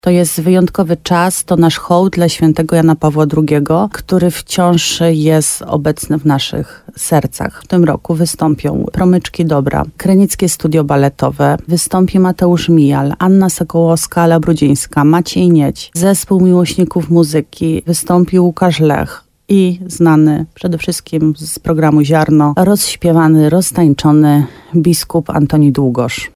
– Będzie można posłuchać dobrej muzyki, modlić się, ale też wspominać dziedzictwo wielkiego Polaka – mówi wójt gminy Łabowa, Marta Słaby.